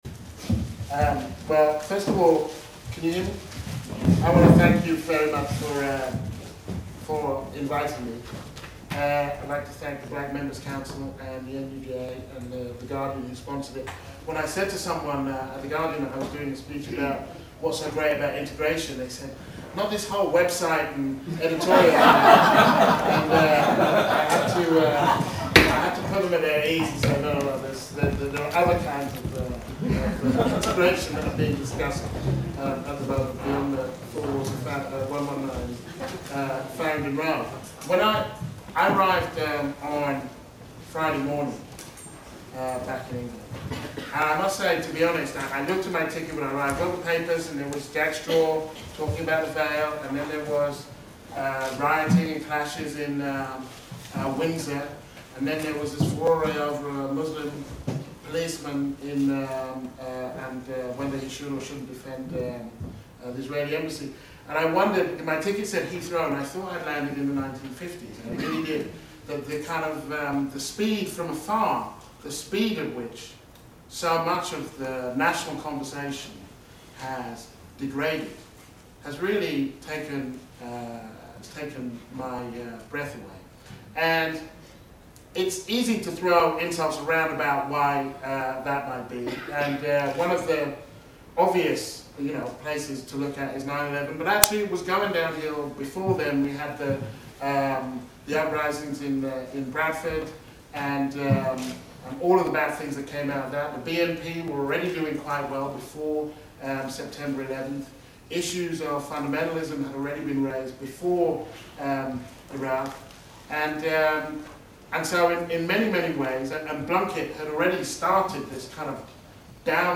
The 5th Claudia Jones Memorial Lecture hosted by the NUJ Black Members' Council and the Guardian.